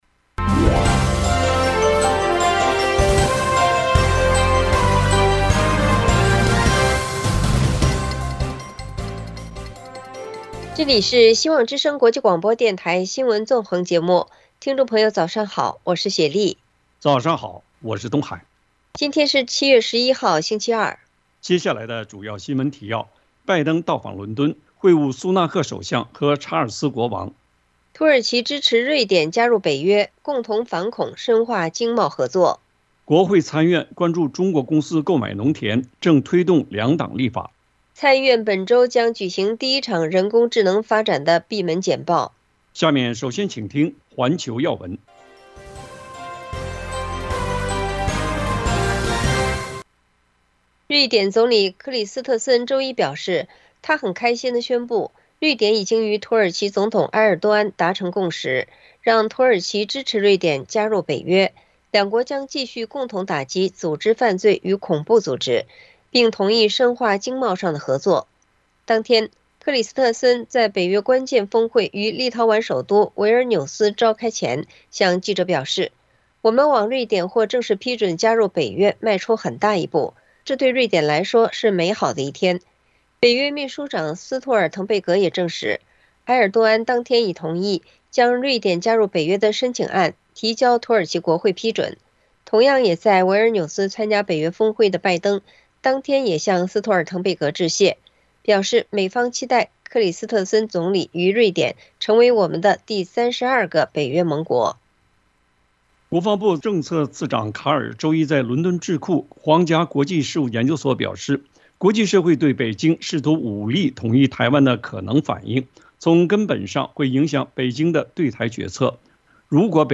新聞提要（上半場） 環球新聞 1、土耳其